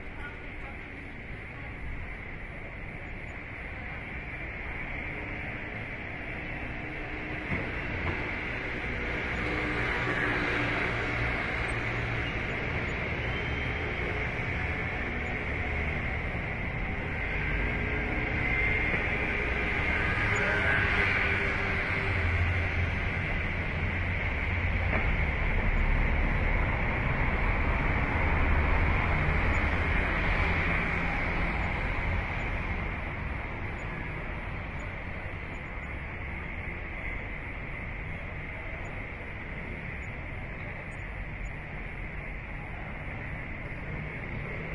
描述：意大利博洛尼亚via Riva Di Reno的交通声，晚上7点50分，Edirol R4
Tag: 声景 腊肠 现场记录 意大利 交通